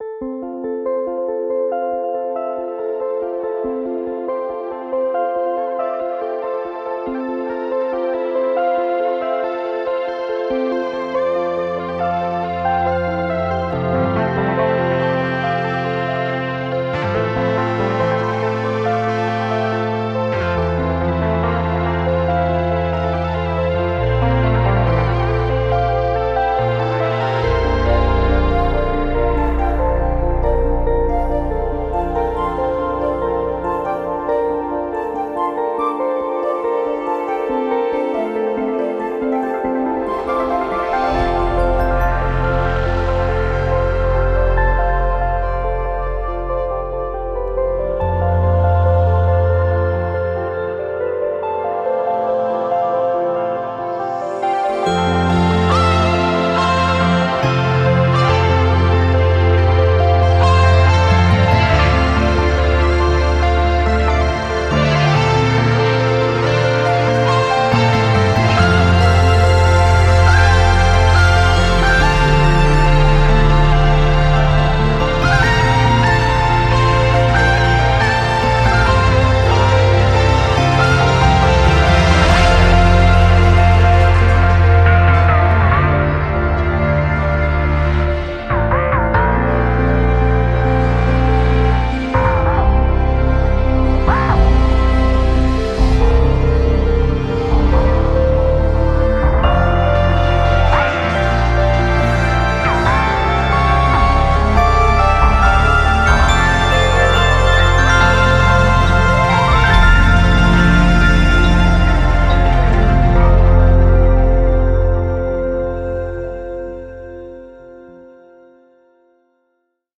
具有针对Omnisphere 2和69个声源的70个新预设的声音集。声音是神奇的，明亮的，流动的，但也有更黑暗和神秘的一面。套装包括铃，吉他，打击垫，ARP，相当多的合唱/人声预设，贝司，主音，合成器，当然还有纹理和音景。